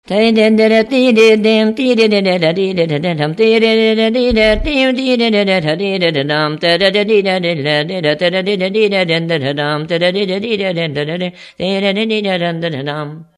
Dallampélda: Hangszeres felvétel
Moldva és Bukovina - Moldva - Klézse
ének